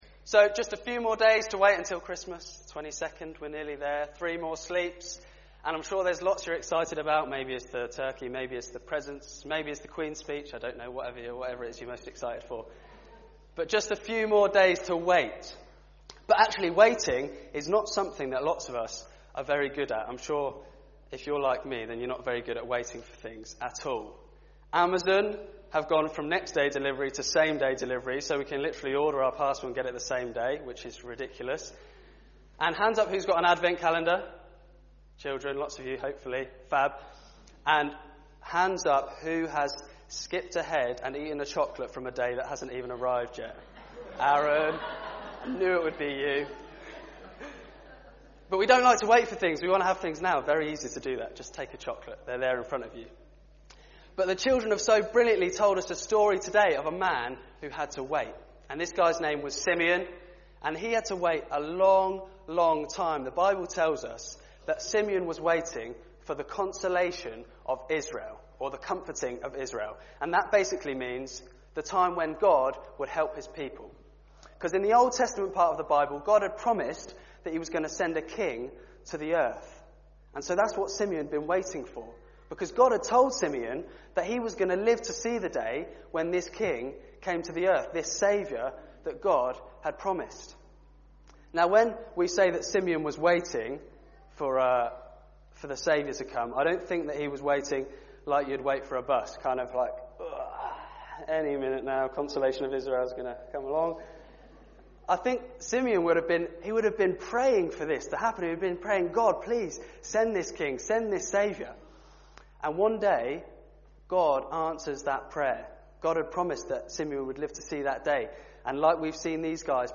Junior Church Nativity 2019